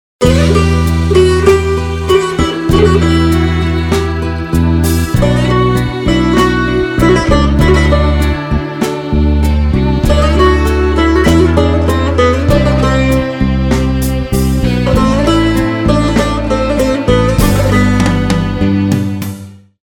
رینگتون غمگین و بی کلام
(برداشتی آزاد از موسیقی های بی کلام خارجی)